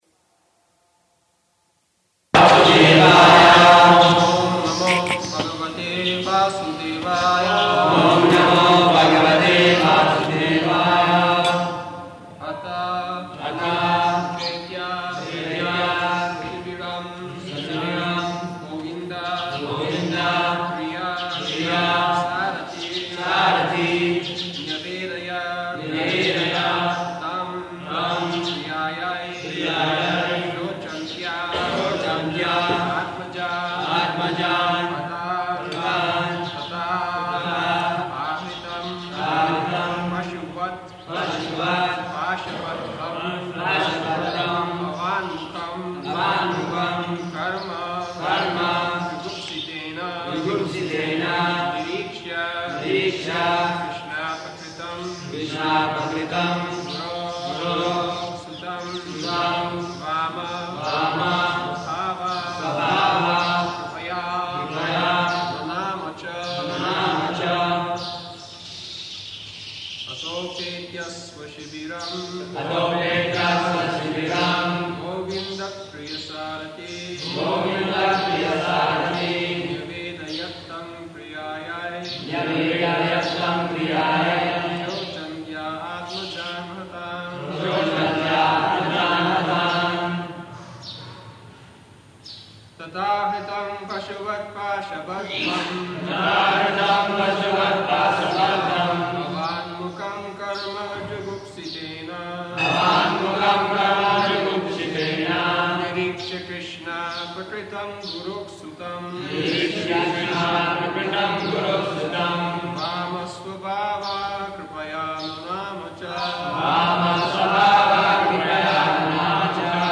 -- Type: Srimad-Bhagavatam Dated: October 2nd 1976 Location: Vṛndāvana Audio file